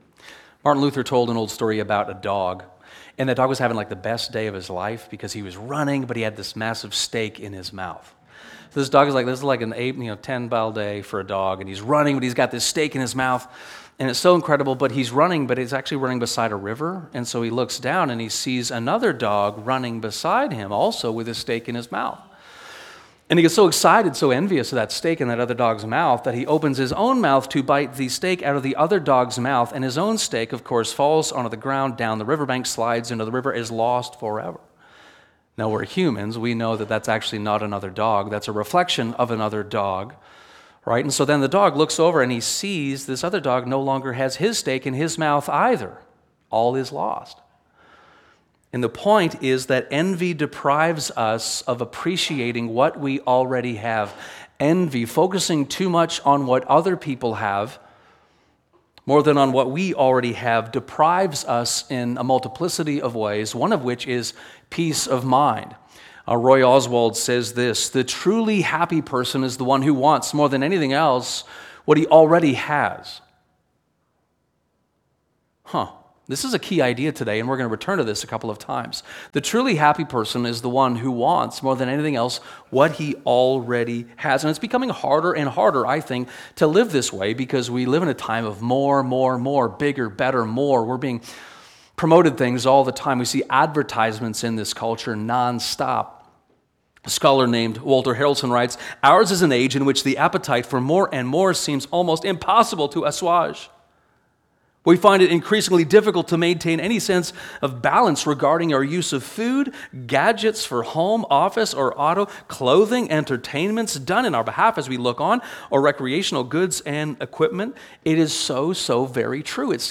This sermon is a part of our series called "The Royal Family Code" and explores commandments eight and ten.